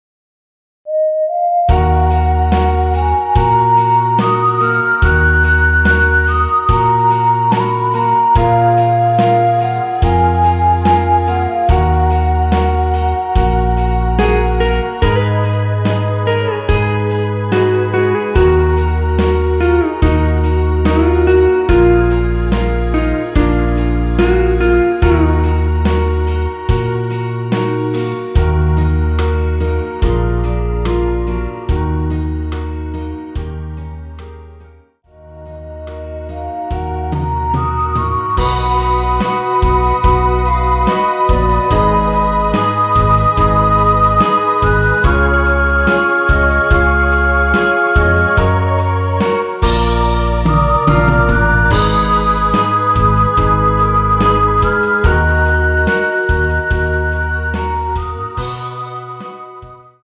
원키에서(+3)올린 멜로디 포함된 MR입니다.
Ebm
앞부분30초, 뒷부분30초씩 편집해서 올려 드리고 있습니다.
중간에 음이 끈어지고 다시 나오는 이유는